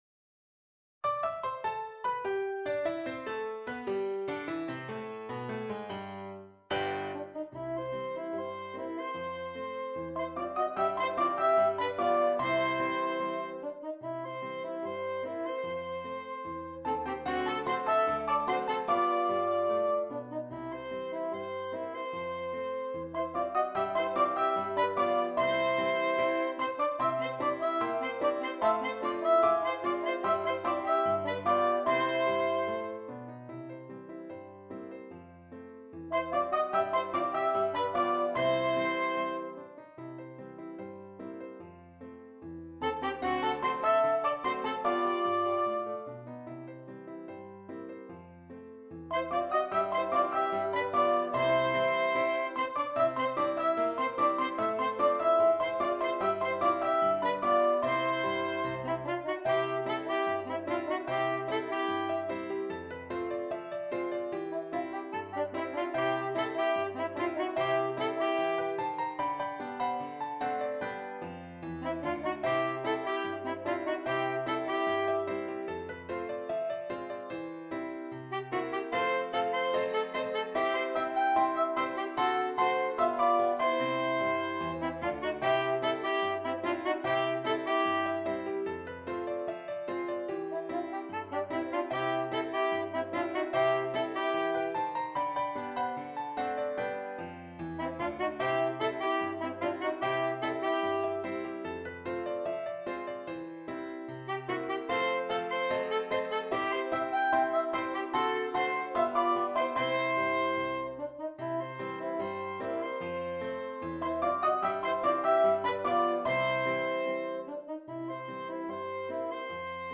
2 Alto Saxophones and Keyboard